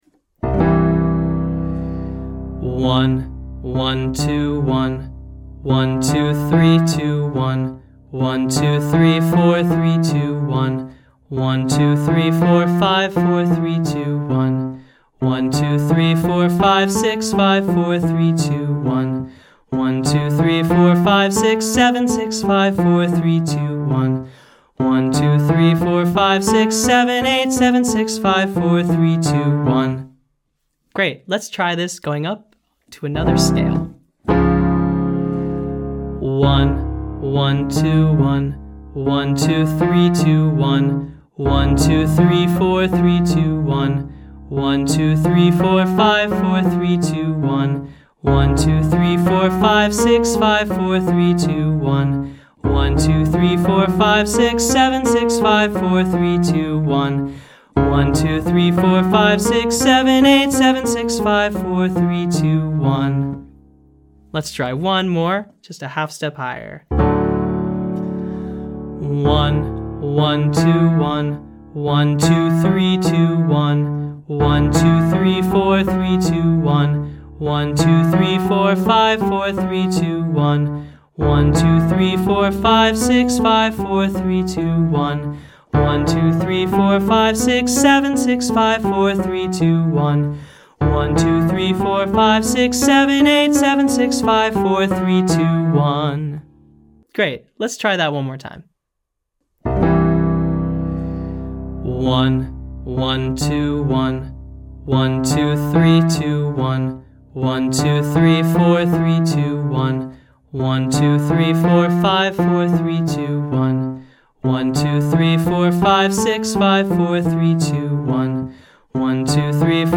Intervals From “1” (“Do”) - Online Singing Lesson
Daily Intonation Practice For Low Voices : Major Scale 4A Daily Intonation Practice For Low Voices : Major Scale 4B Daily Intonation Practice For Low Voices : Major Scale 4C Daily Intonation Practice For Low Voices : Major Scale 4D